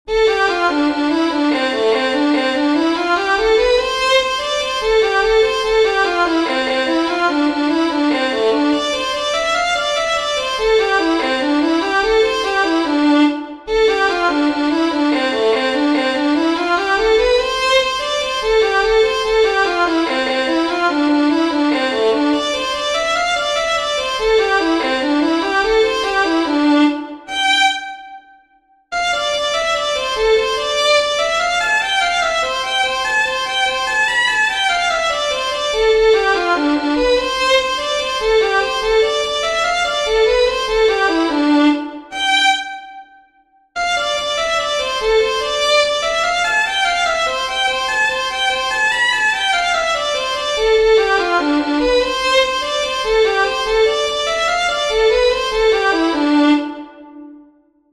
LITTLE-RED-GIG-REEL.mp3